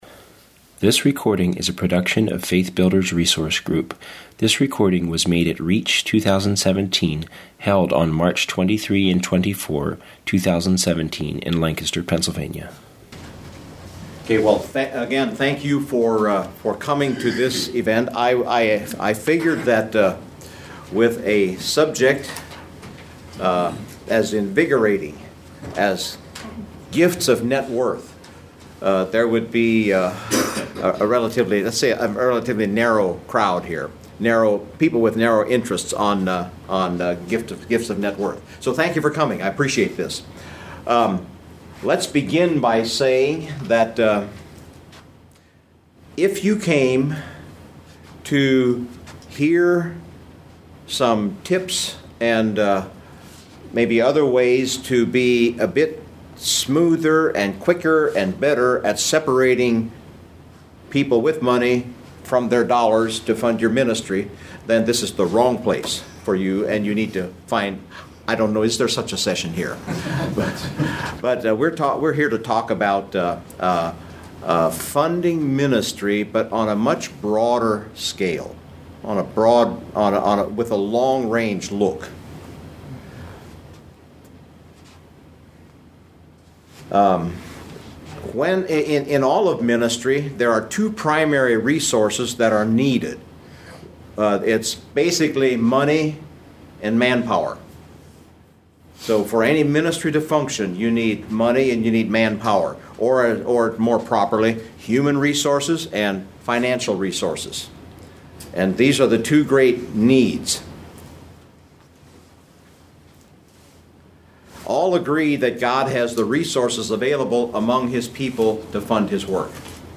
Home » Lectures » Funding Your Ministry with Gifts of Net Worth